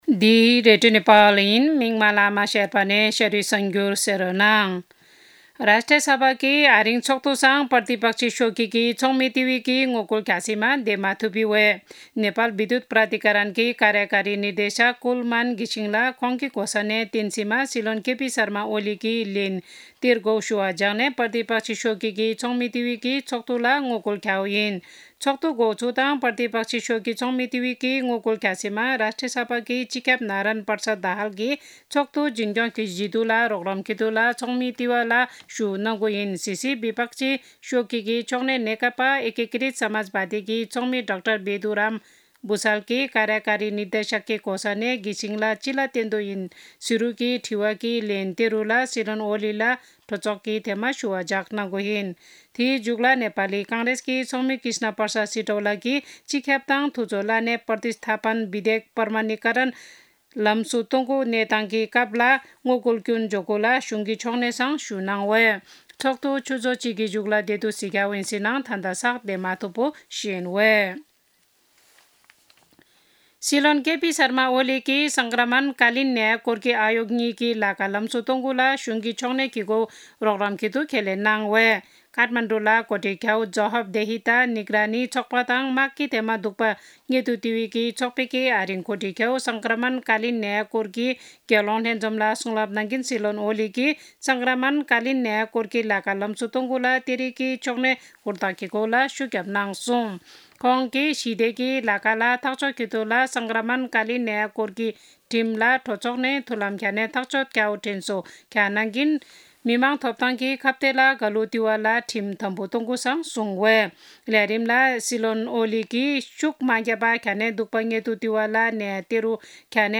शेर्पा भाषाको समाचार : १४ चैत , २०८१
shearpa-news-1-2.mp3